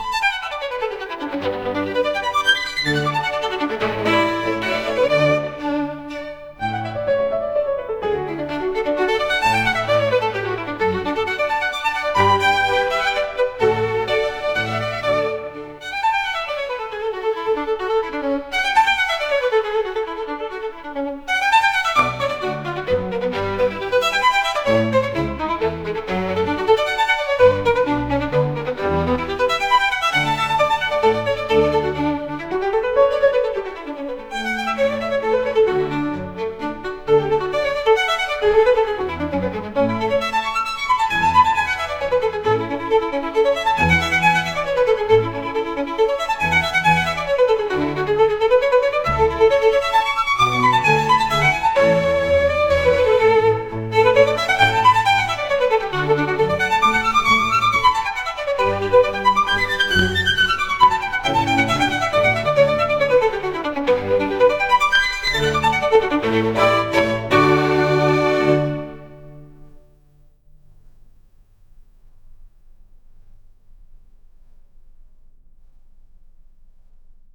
高貴な貴族が朝食を食べている風景で流れるようなバイオリン曲です。